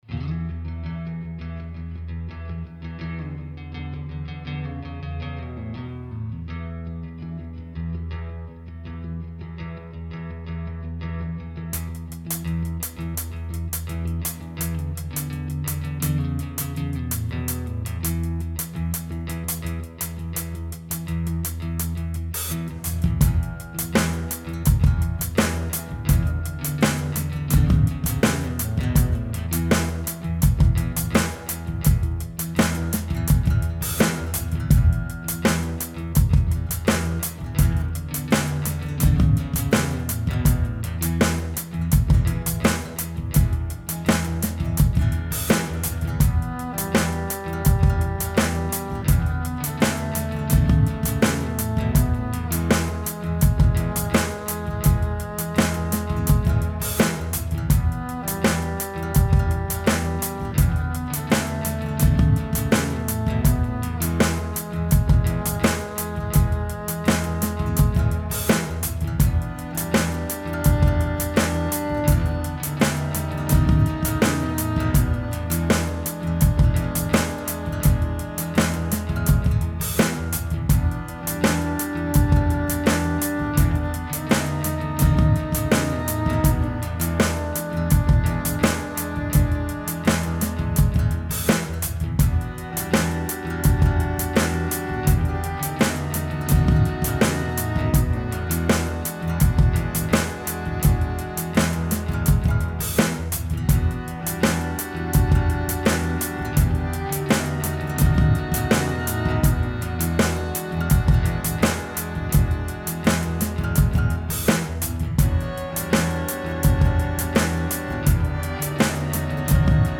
guitar, drums
violin, banjo
minimalism, blues, noise, jazz, soul, country